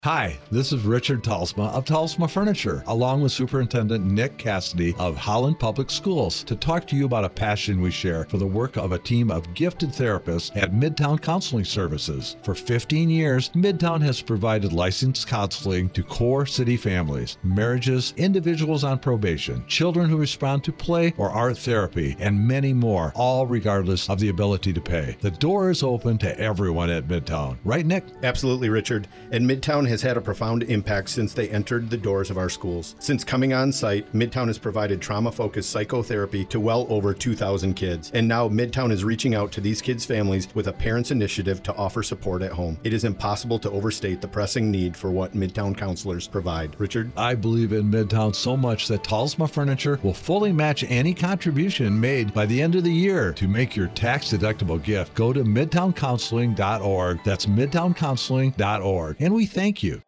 60-second radio spot on WHTC-AM and the VAN - FM.